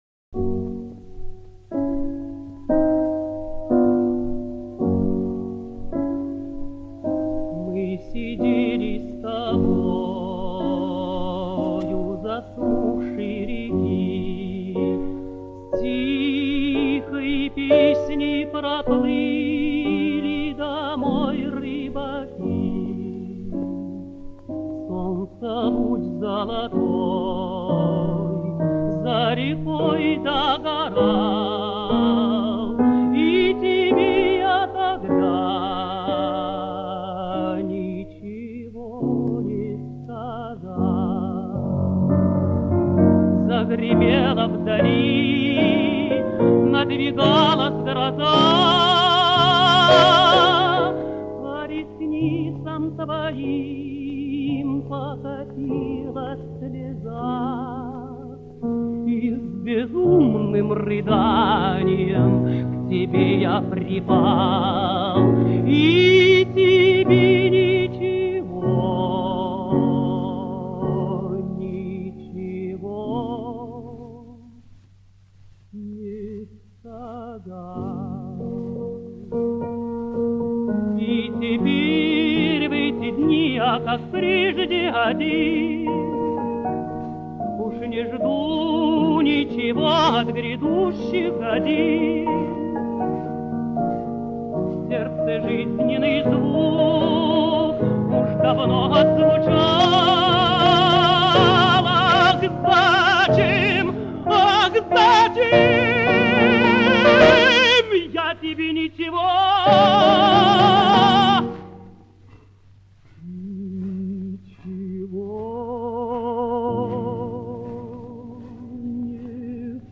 Обладал красивым, «полётным», свободно звучащим голосом, особенно в верхнем регистре.
Романс «Мы сидели с тобой». Исполняет И. Д. Жадан.